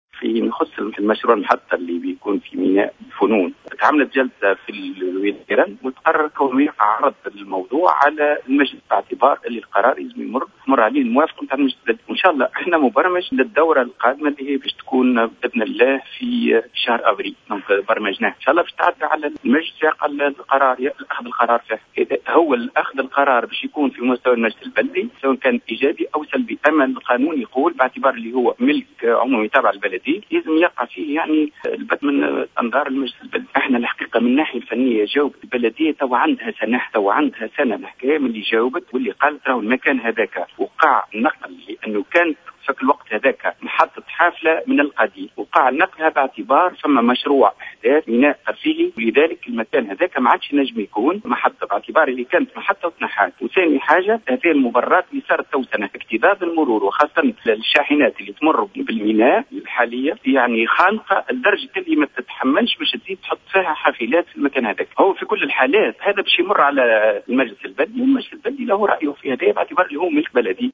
من جانبه،أكد رئيس النيابة الخصوصية لبلدية سوسة محمد المكني في تصريح ل"جوهرة أف أم" أن الموضوع سيعرض على المجلس البلدي الذي سيبُت في هذا الملف خلال شهر أفريل القادم.